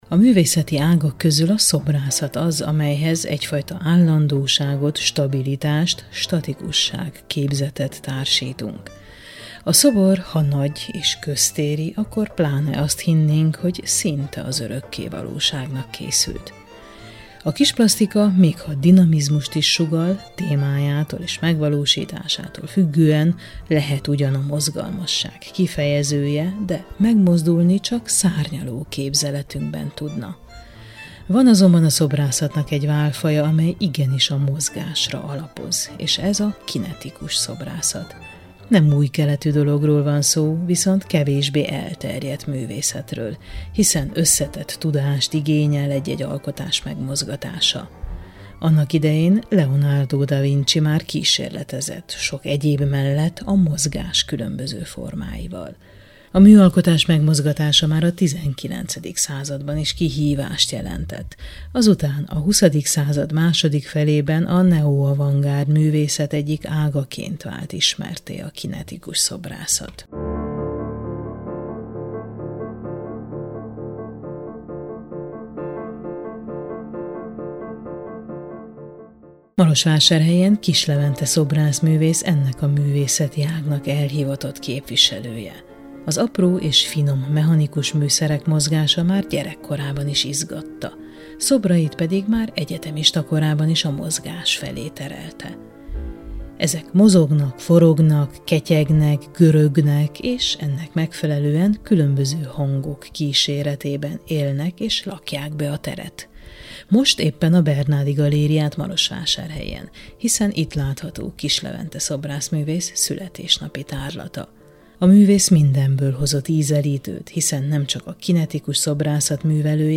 Alkotásról, dilemmákról, forma és játék viszonyáról beszélgetünk